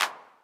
Index of /m8-backup/M8/Samples/classic machines/909/Clap
Clap Accent.wav